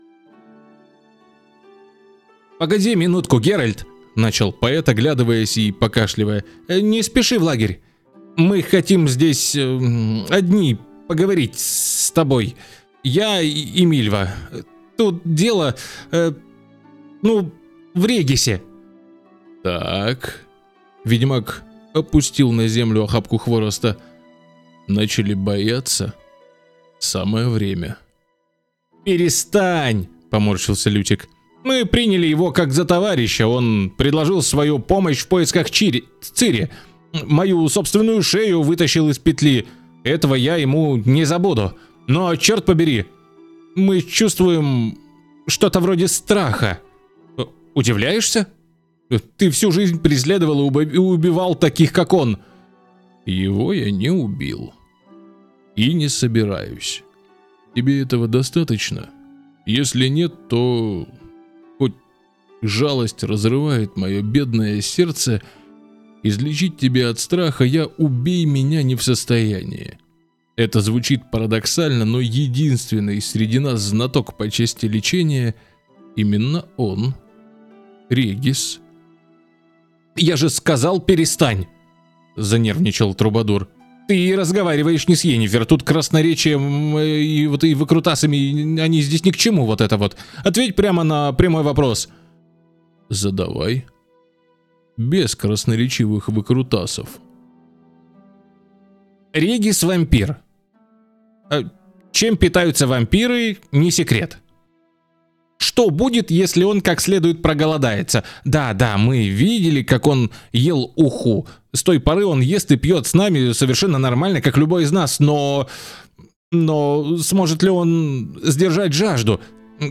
Муж, Аудиокнига
Работаю в Reaper'е, микрофон - Fifine AM8 (динамический, проверенный временем и тоннами текста)